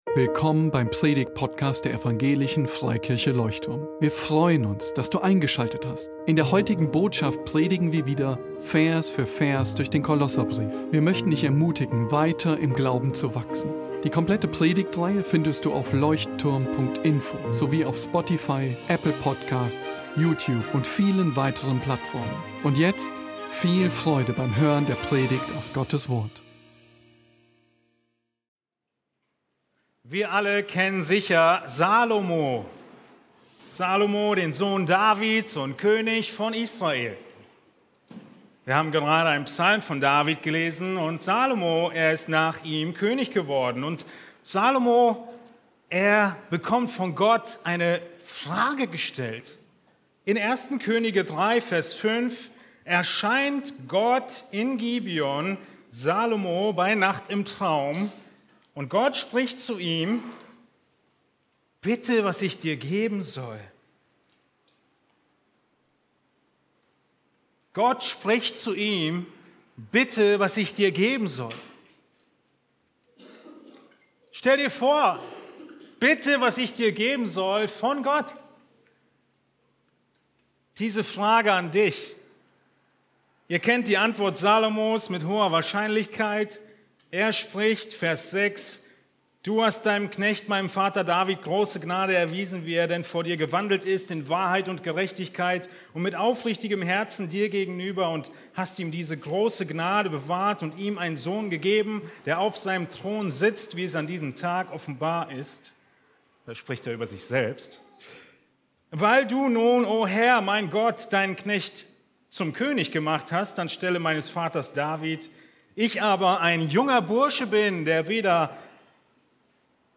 Besuche unseren Gottesdienst in Berlin.